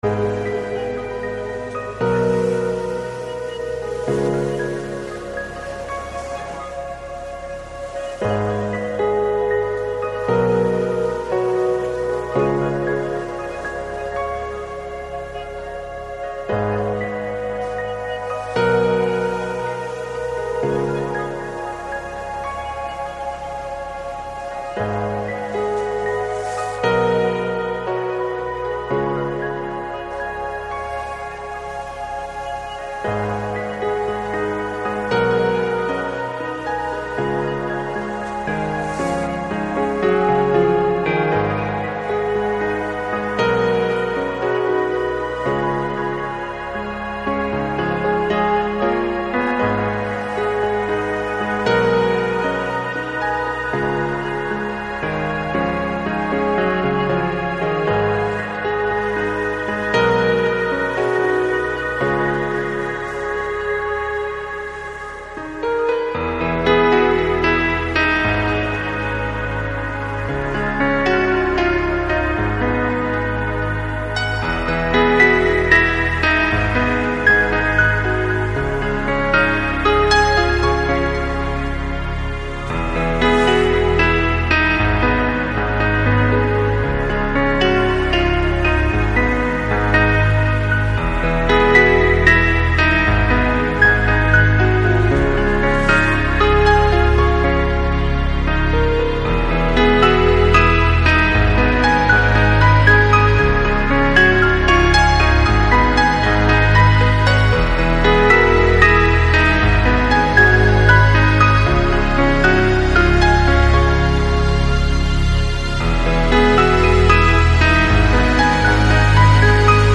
(Vocal-Trance Chill Mix)
Genre: Chillout, New Age, Enigmatic, Ambient
(Chill Out Mix)